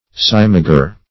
simagre - definition of simagre - synonyms, pronunciation, spelling from Free Dictionary Search Result for " simagre" : The Collaborative International Dictionary of English v.0.48: Simagre \Sim"a*gre\, n. [F. simagr['e]e.]